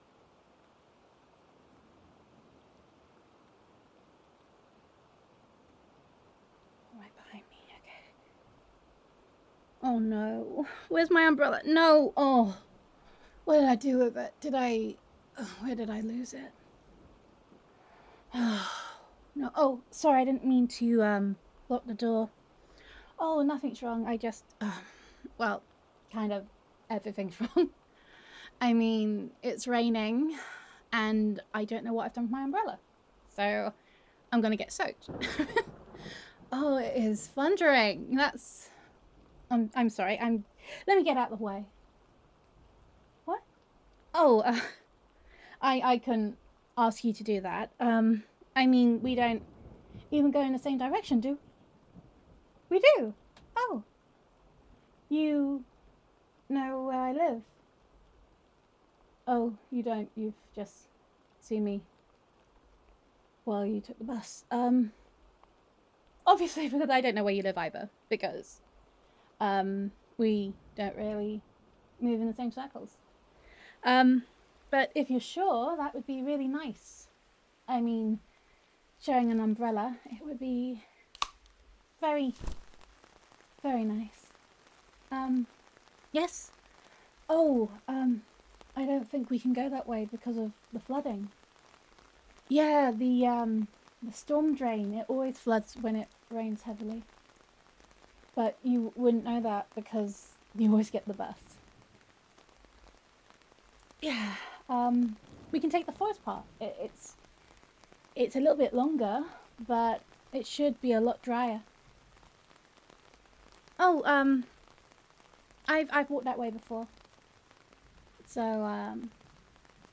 [F4A]